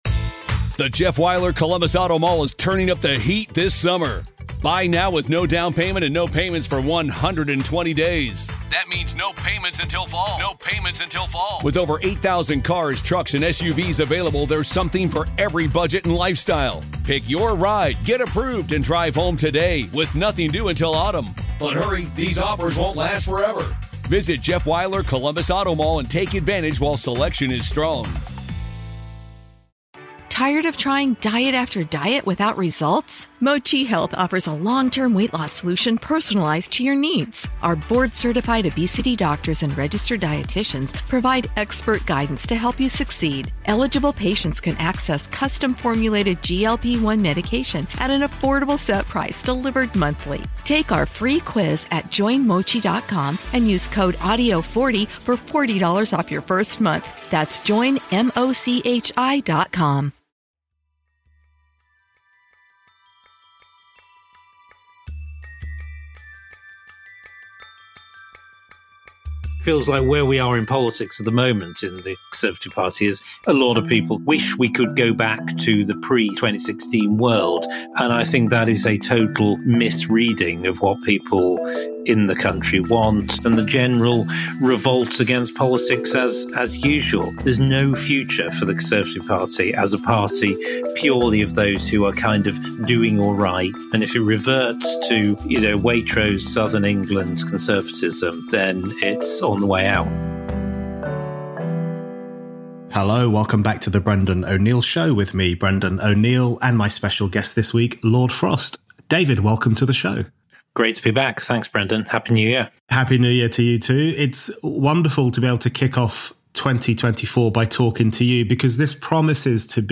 David Frost – former chief Brexit negotiator and Conservative peer – returns for this episode of The Brendan O’Neill Show. David and Brendan discuss the betrayal of the Red Wall and why Rishi Sunak cannot afford to ignore the culture war.